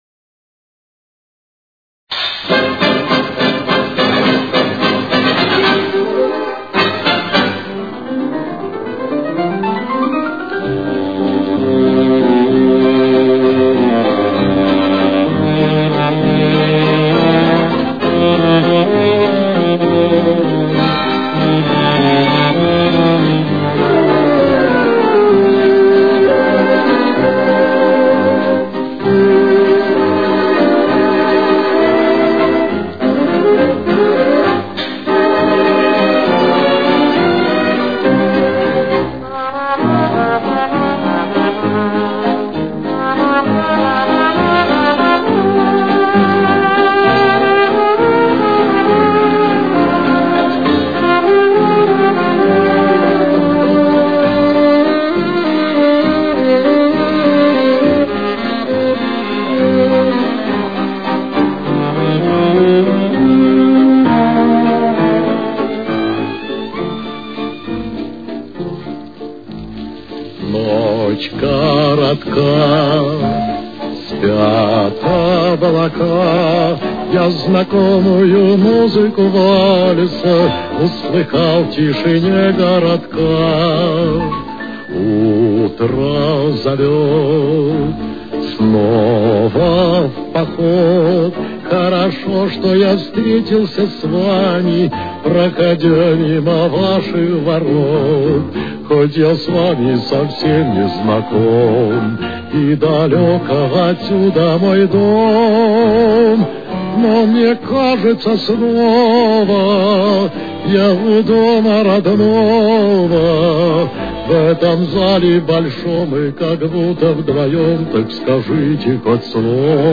Темп: 203.